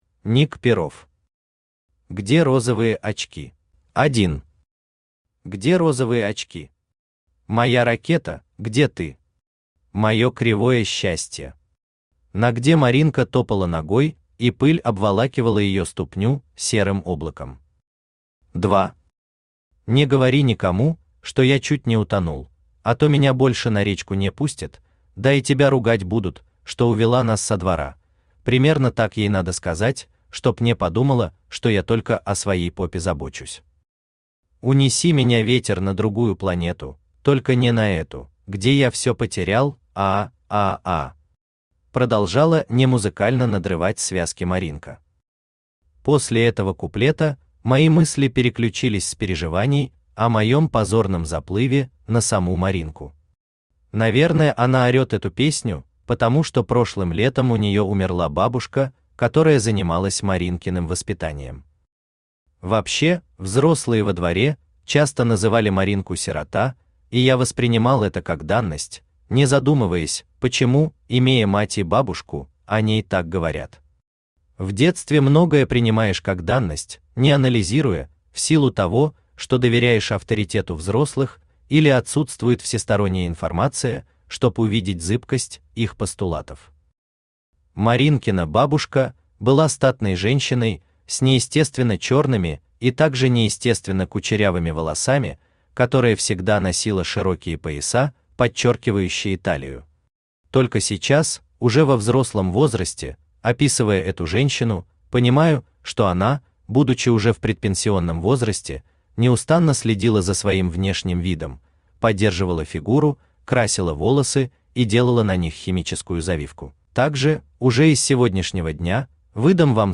Аудиокнига Где розовые очки | Библиотека аудиокниг
Aудиокнига Где розовые очки Автор Ник Перов Читает аудиокнигу Авточтец ЛитРес.